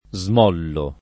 smollare v.; smollo [ @ m 0 llo ]